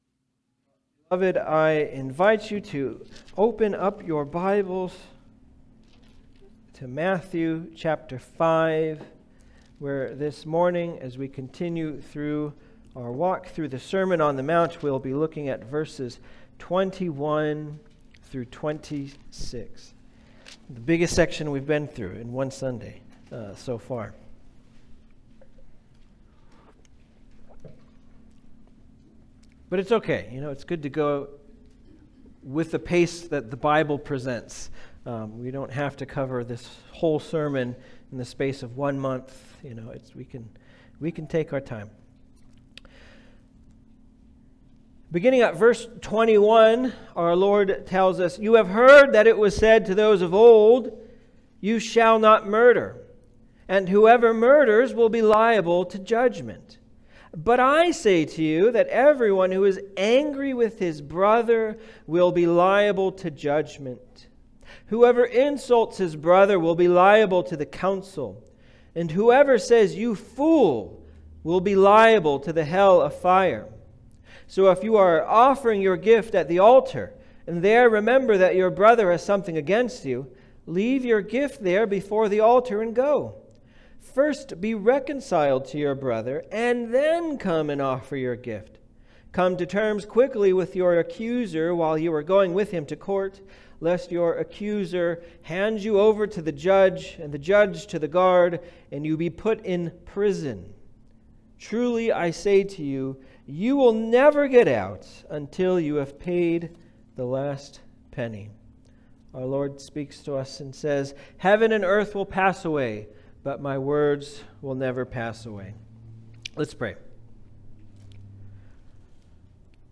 Passage: Matthew 5:21-26 Service Type: Sunday Service